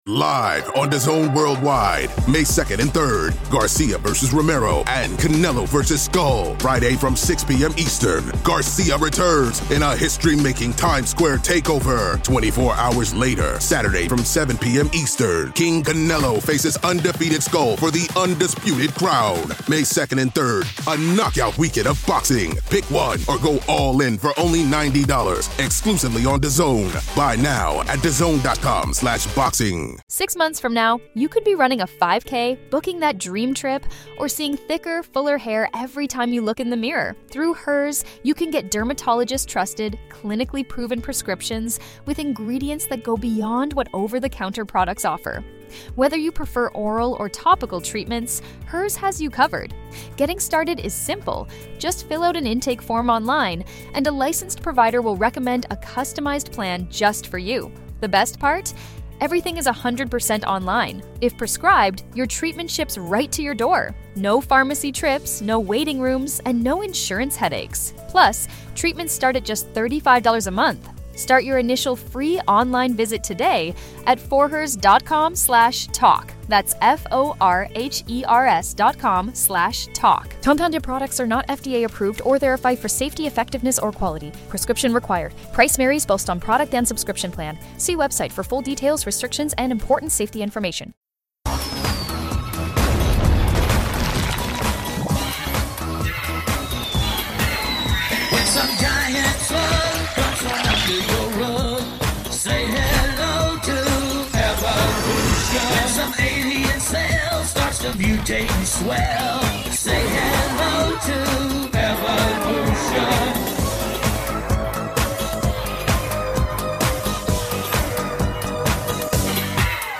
A new MP3 sermon
Event: Sunday - AM